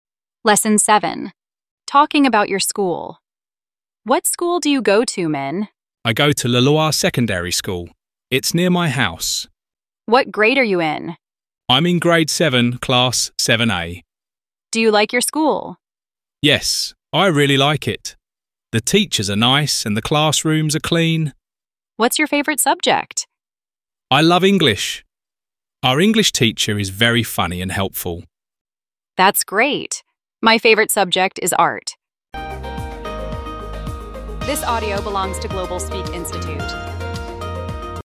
Giọng tự nhiên